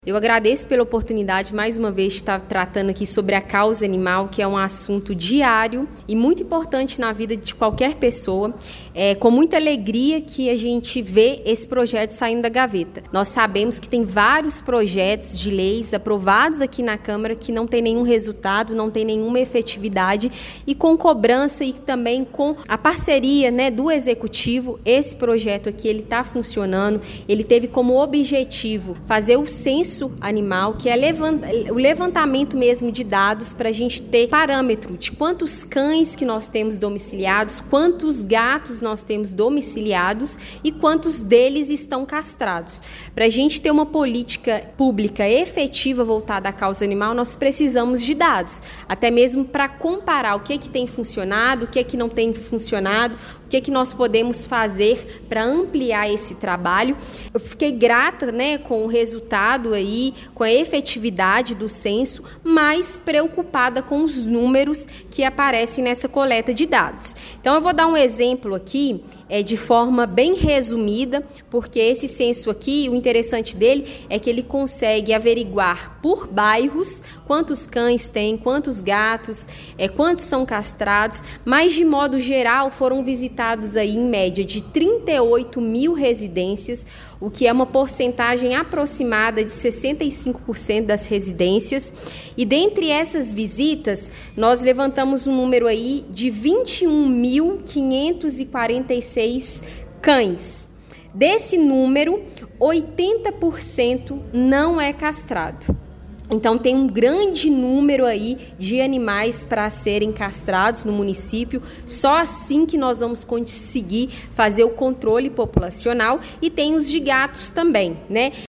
O Portal GRNEWS acompanhou a primeira reunião ordinária da Câmara Municipal em 2026, realizada nesta terça-feira, 20 de janeiro, a vereadora Camila Gonçalves de Araújo (PSDB), a Camila Mão Amiga, apresentou os resultados iniciais da primeira coleta do Censo Municipal de Animais Domésticos.